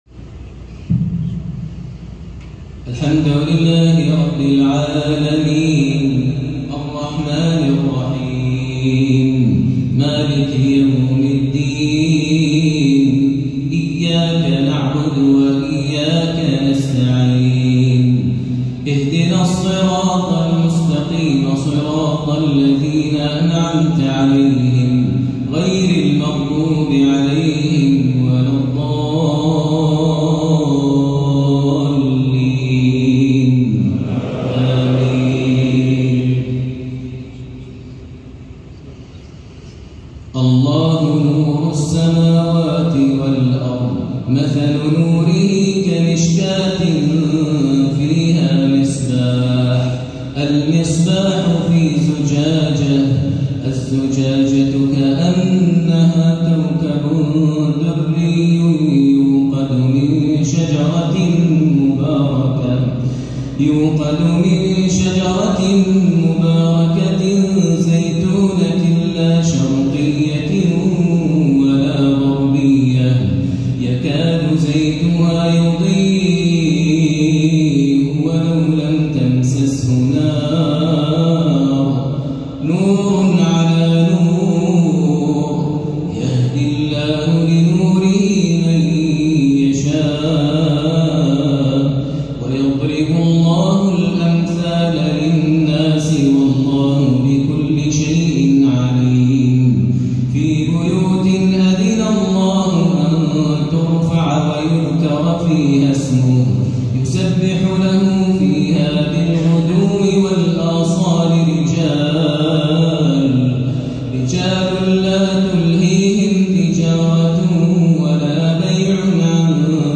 صلاة الشيخ ماهر المعيقلي في افتتاح أحد مساجد مكة المكرمة | عشاء الجمعة 4-8-1444هـ > إمامة الشيخ ماهر المعيقلي وجهوده الدعوية داخل السعودية > المزيد - تلاوات ماهر المعيقلي